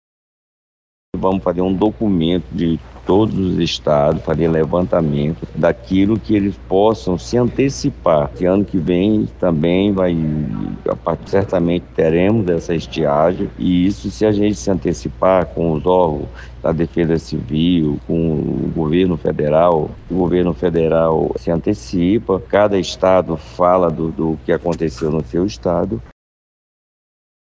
Para isso, será elaborado um documento contendo informações detalhadas fornecidas pelos estados afetados, como explica o deputado estadual Adjuto Afonso.
Sonora-Adjuto-Afonso.mp3